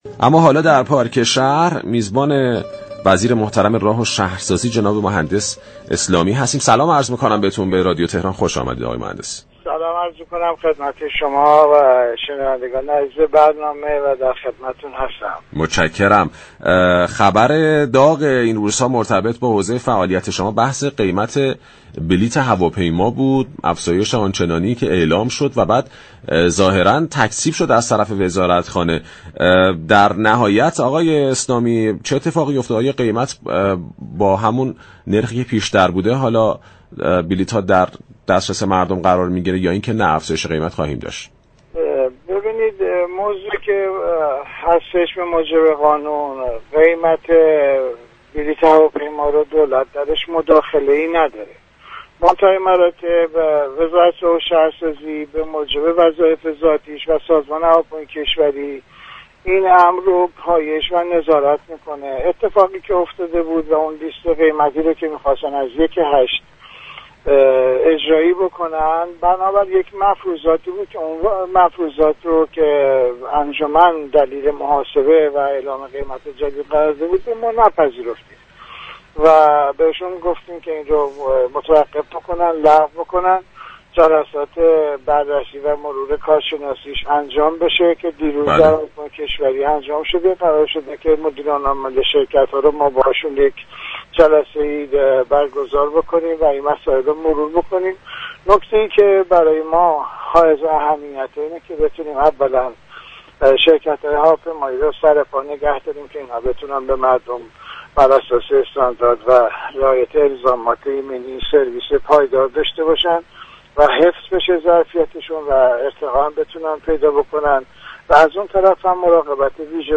محمد اسلامی وزیر راه و شهرسازی در گفتگو با پارك شهر گفت: بر اساس دستور رئیس جمهور از این پس نظارت بر بنگاه ها و دفاتر مشاور املاك و مسكن توسط وزارت راه و شهرسازی صورت می گیرد.